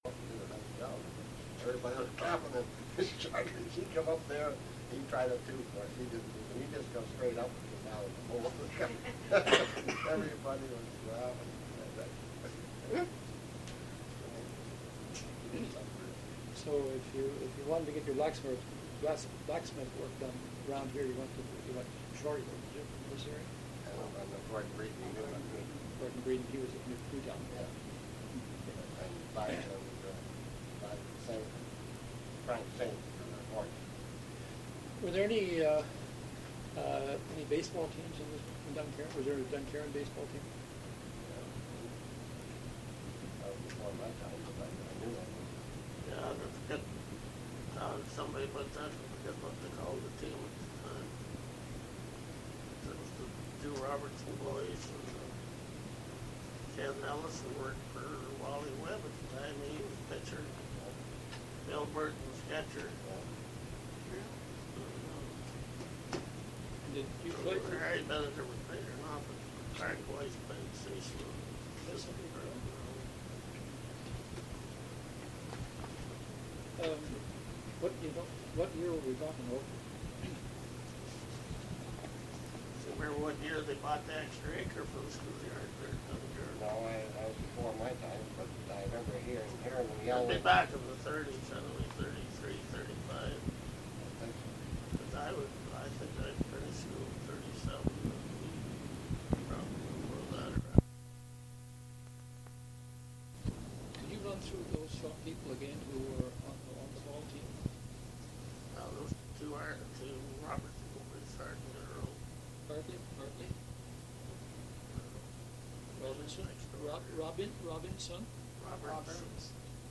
Second part of a group interview on July 6, 2004 with various people who have lived in the community of Dunkerron.